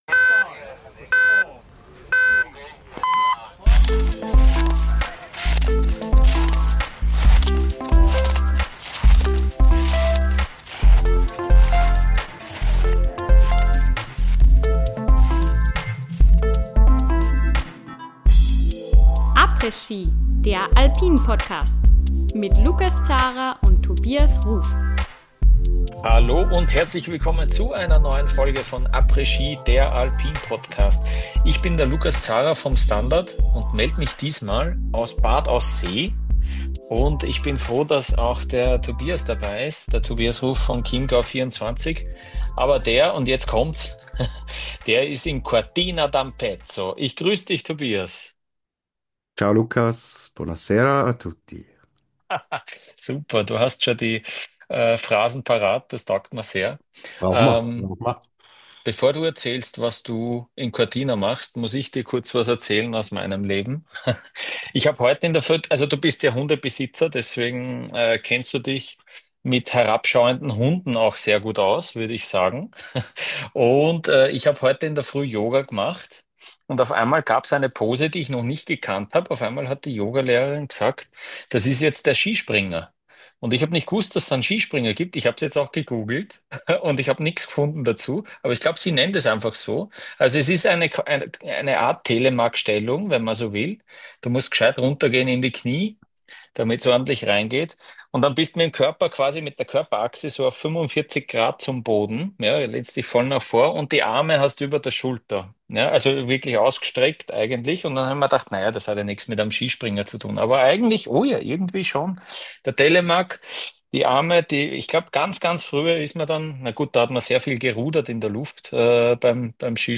Neue Folge aus den Außenstudios in Bad Aussee und aus einem überraschend ruhigen Cortina d'Ampezzo!